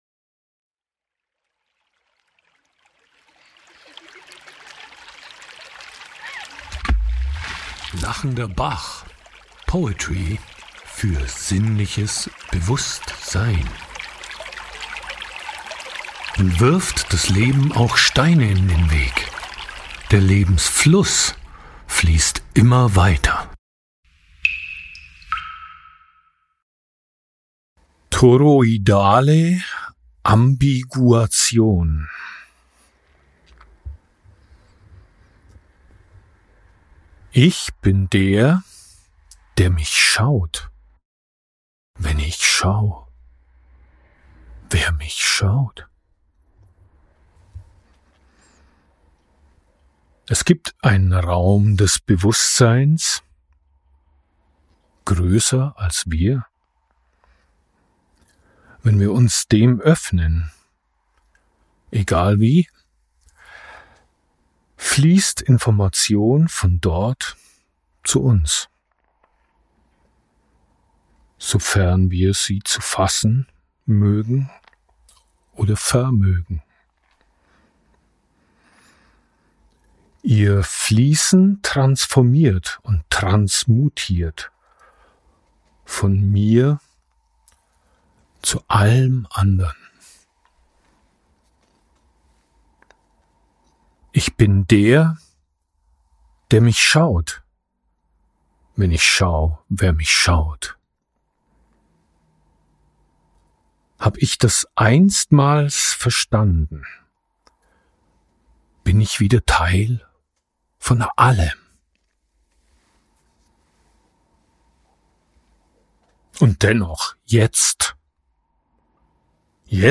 A note for the Spotify team: All music used in this episode has been created by myself using tools and samples from Logic Pro X.